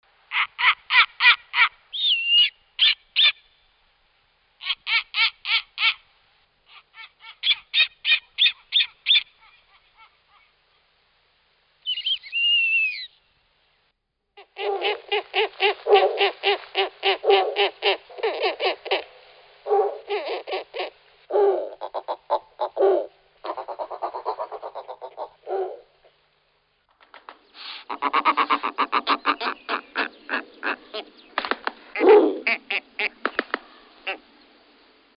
Звуки белой совы
Белая сова